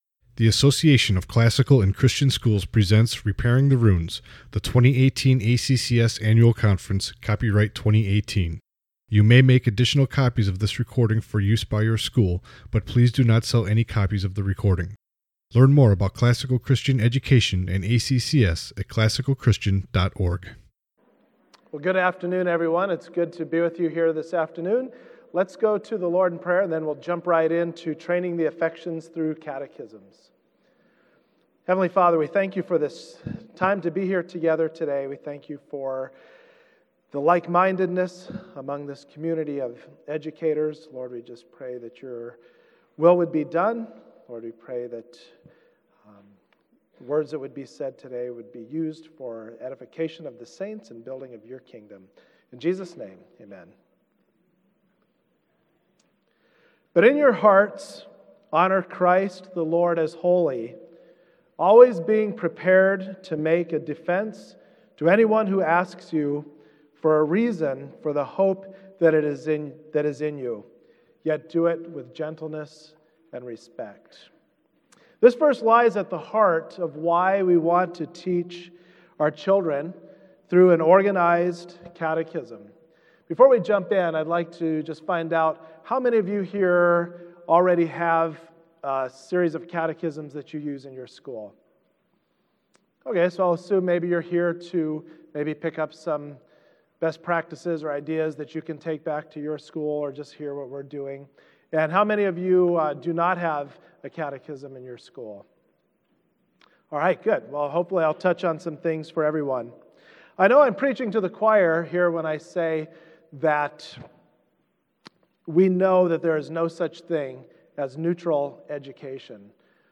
Jan 15, 2019 | Conference Talks, Foundations Talk, General Classroom, K-6, Library, Media_Audio | 0 comments
Additional Materials The Association of Classical & Christian Schools presents Repairing the Ruins, the ACCS annual conference, copyright ACCS.